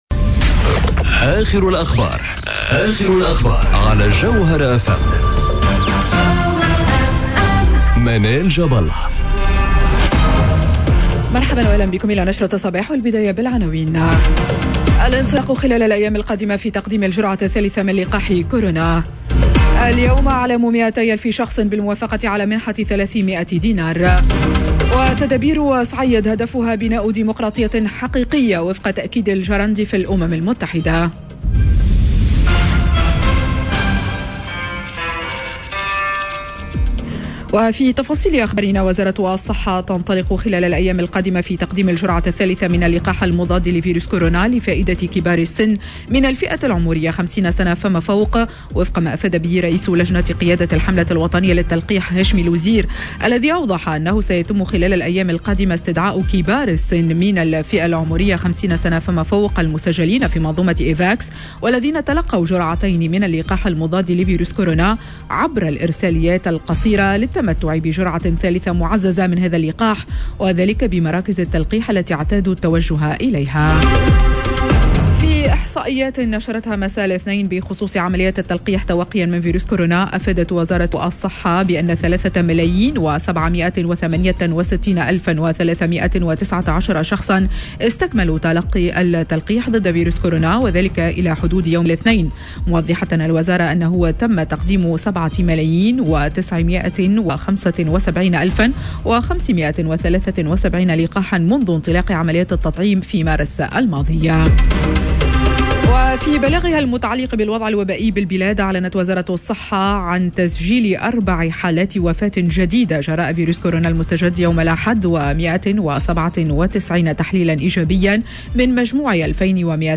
نشرة أخبار السابعة صباحا ليوم الثلاثاء 28 سبتمر 2021